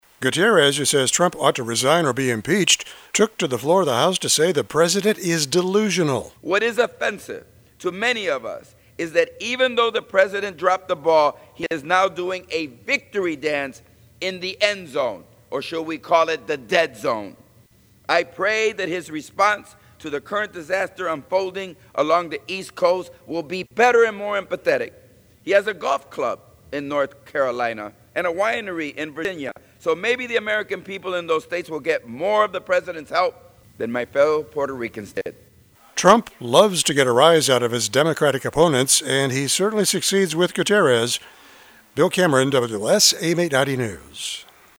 Gutierrez, who says Trump ought to resign or be impeached, took to the floor of the House to say the president is “delusional.”